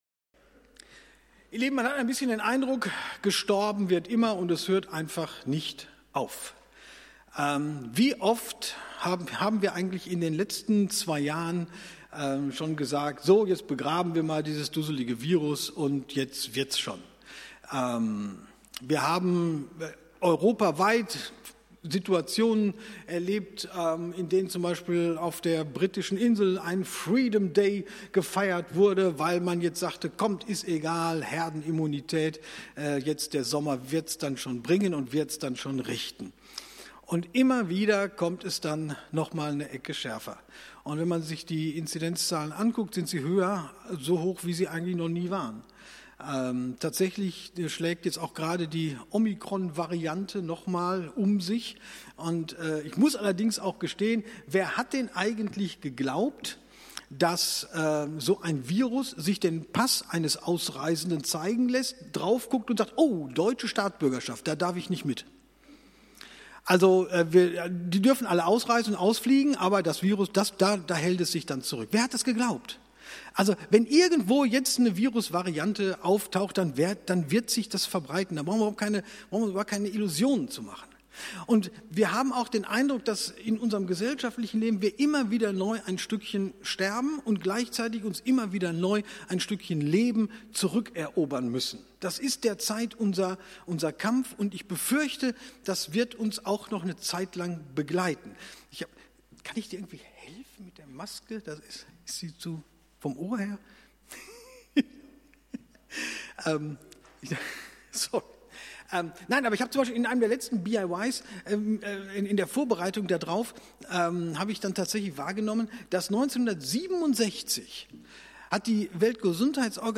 Gottesdienst zum 1. Advent
Dez. 14, 2021 | Predigten | 0 Kommentare